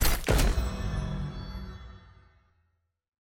sfx_ui_map_panel_stamp.ogg